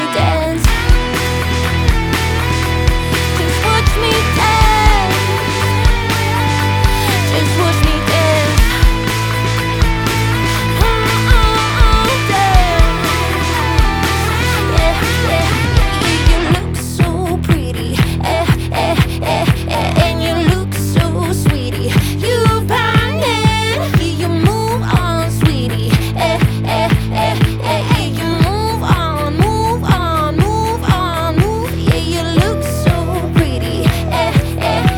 Жанр: Поп / Рок